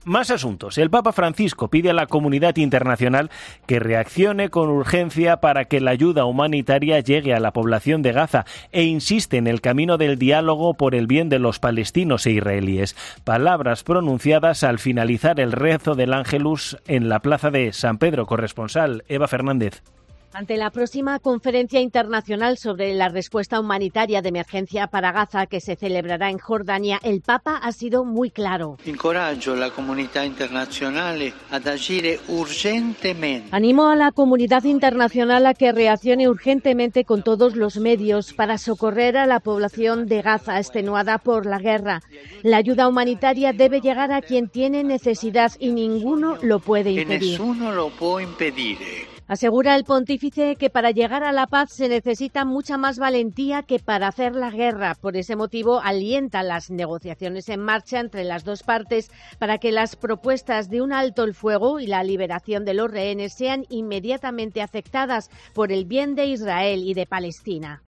"Aliento a la comunidad internacional a actuar urgentemente por todos los medios para ayudar a la población de Gaza, devastada por la guerra", dijo Francisco durante sus palabras en el rezo del ángelus en la plaza San Pedro.